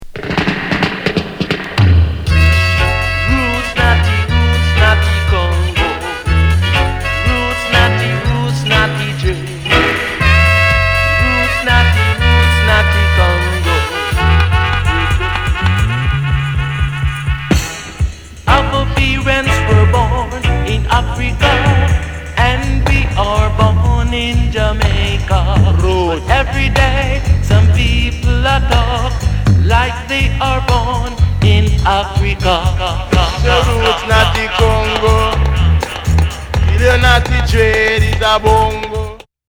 雷みたいなイントロ・ドラムロールから攻撃的なホーン、
トーク・オーヴァー！！最高！！